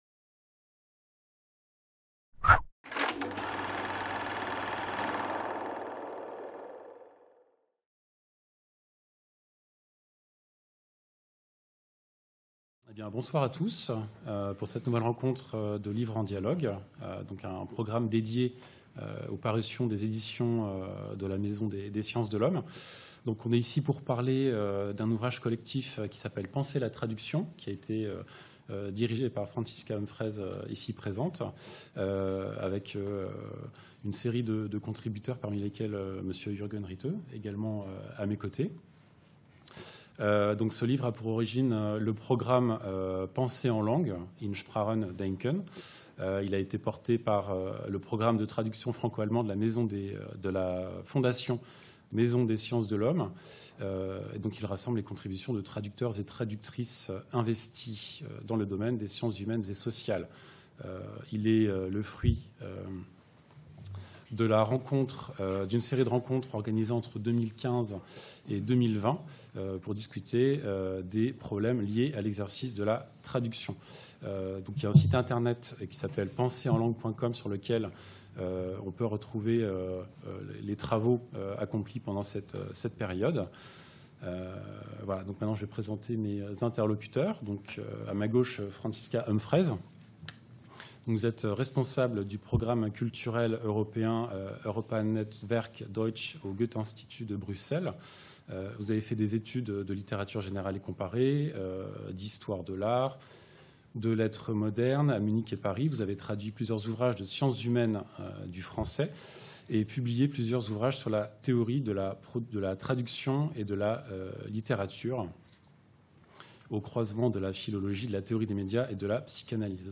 SOIRÉE DE PRÉSENTATION DE L'OUVRAGE "PENSER LA TRADUCTION" | Canal U
À l'issue de la discussion, vous aurez la possibilité de poser toutes vos questions à nos invités.